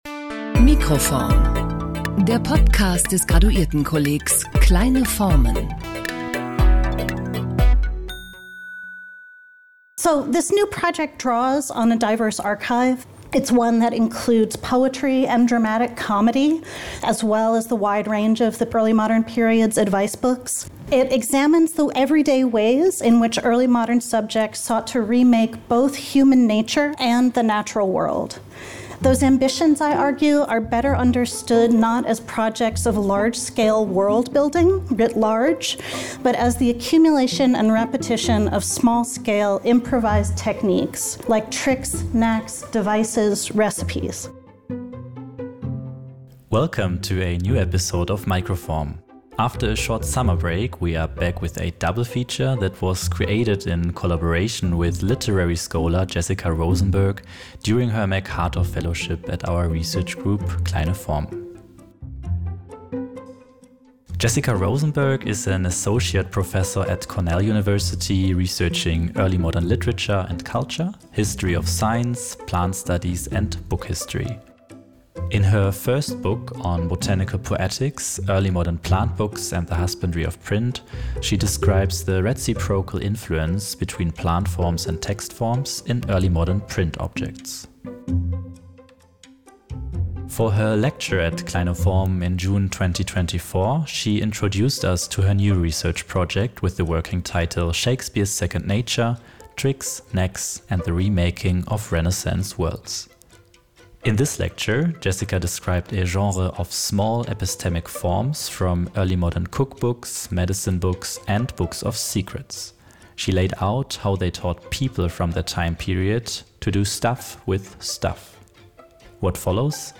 Her public lecture on “The Theory of the Knack” marked the start of an intense and continued exchange.
Vortrag